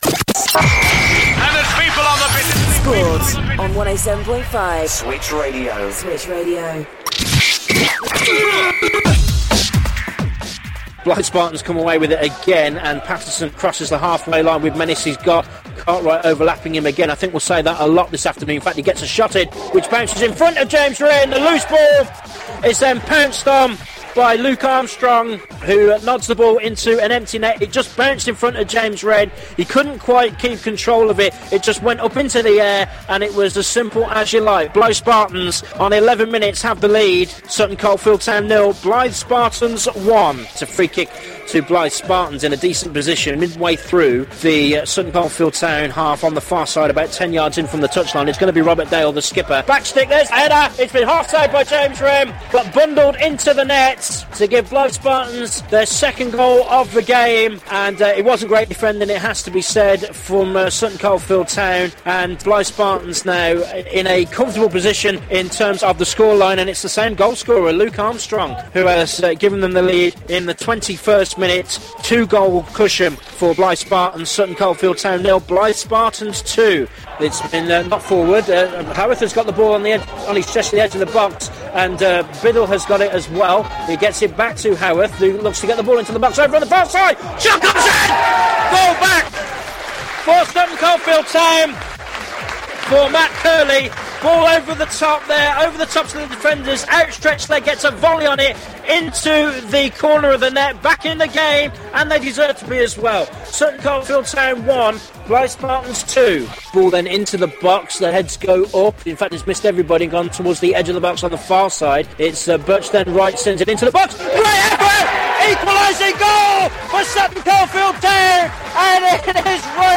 Match commentary highlights